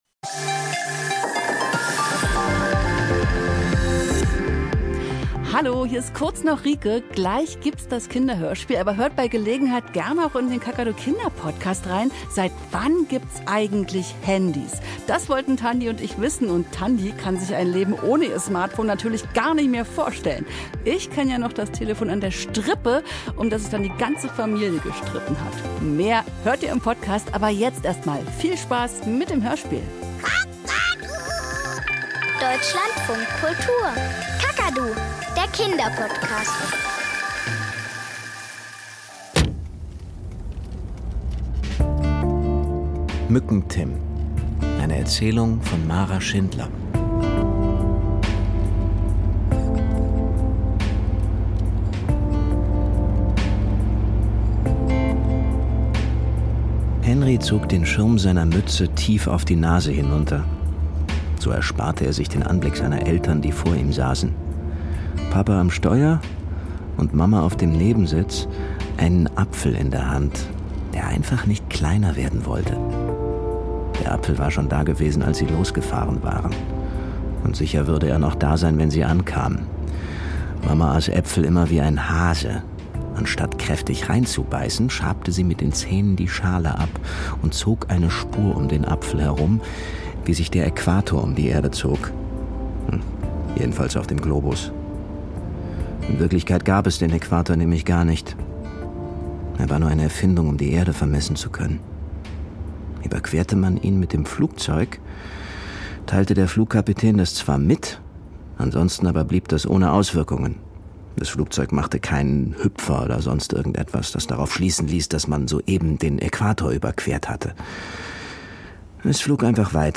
Kinderhörspiel und Geschichten Mücken-Tim 22:34 Minuten Egal wie nett Timea ist, das Baumhaus gehört Henri und sie hat darin nichts zu suchen.